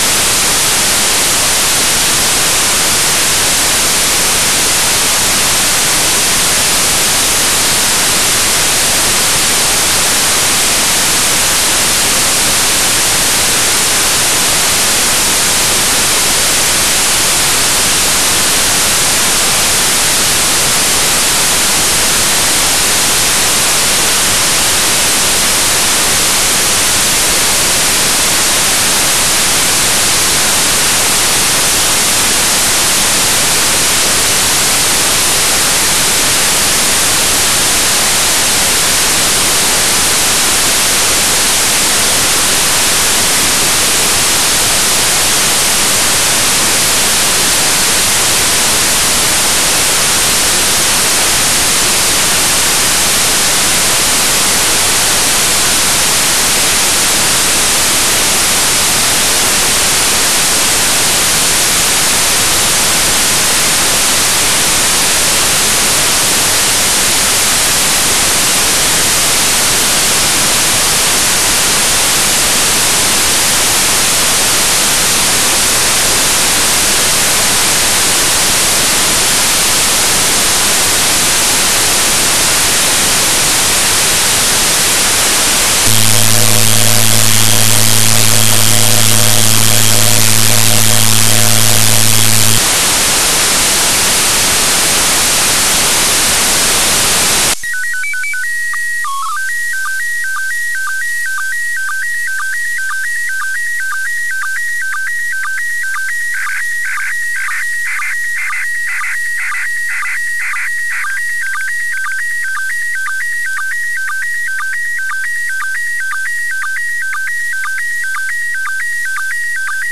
Como puedes observar, el audio tiene una duración de aproximadamente 10 minutos, esto se debe a que hay unos 2 minutos de silencio de radio (ruido sin mensaje) entre imagen e imagen transmitida.
Si tu imagen es muy ruidosa prueba a decodificarla desde el archivo de audio descargado, es probable que el audio haya perdido calidad al subirlo a esta plataforma.
La primera imagen aparece en el minuto 1:37.
Debido al ruido, no es posible decodificarla correctamente.